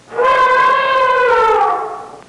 Elephant Talk Sound Effect
Download a high-quality elephant talk sound effect.
elephant-talk.mp3